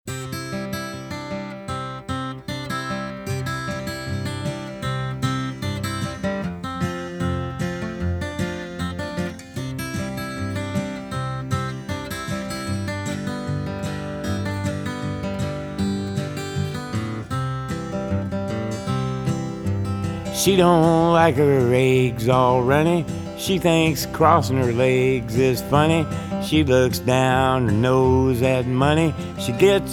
• Folk-Rock